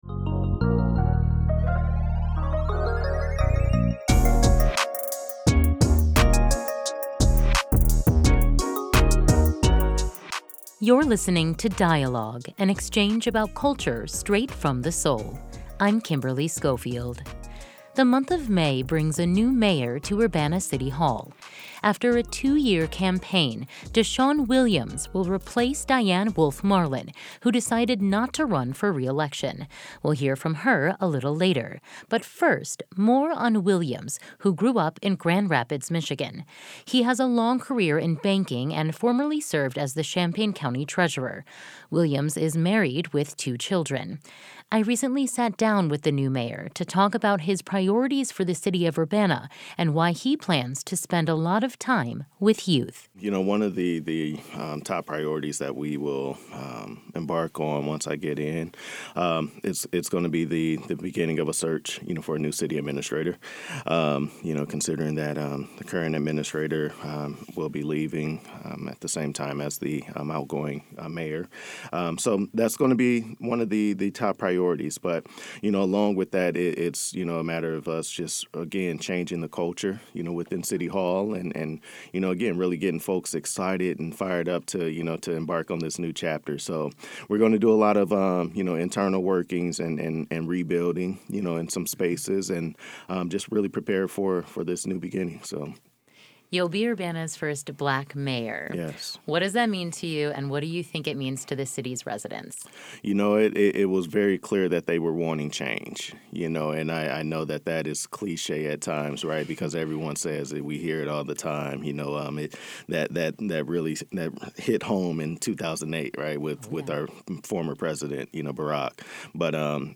A Dialogue with incoming Urbana mayor DeShawn Williams on plans for the city